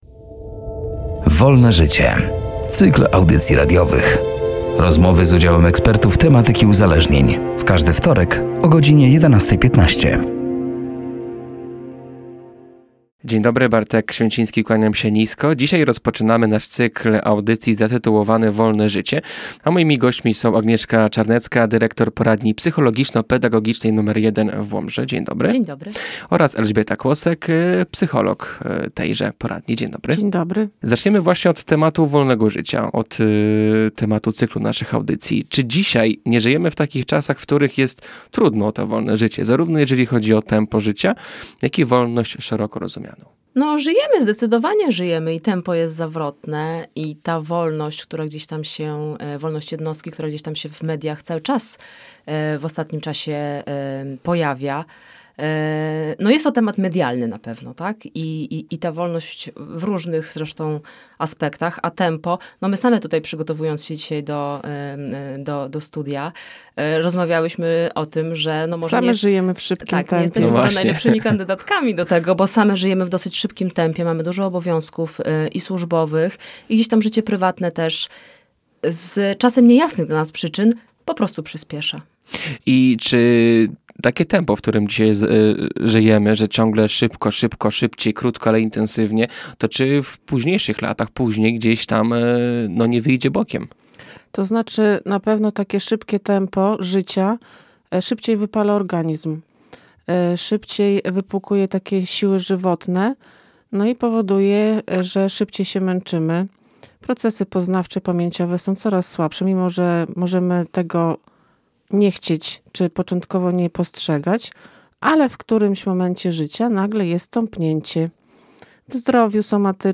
„Wolne życie” to cykl audycji radiowych. Rozmowy z udziałem ekspertów z obszaru psychologii i uzależnień.